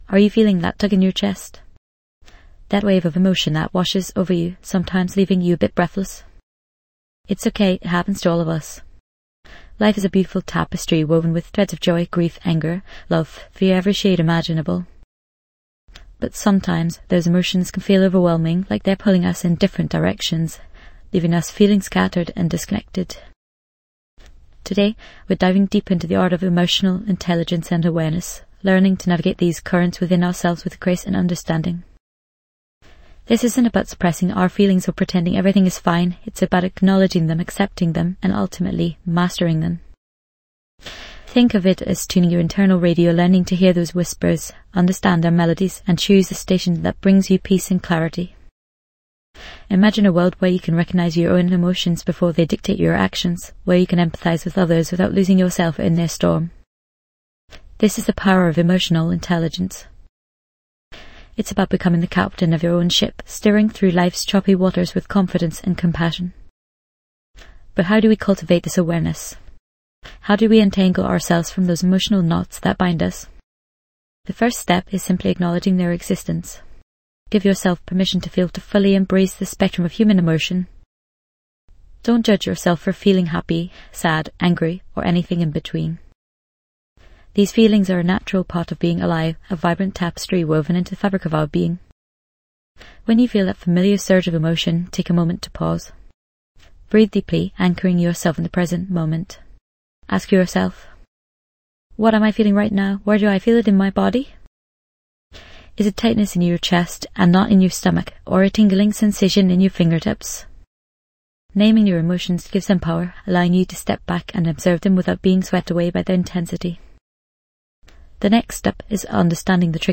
This enlightening guided meditation aims to help you identify, understand, and manage your emotions effectively, fostering a more balanced and harmonious life.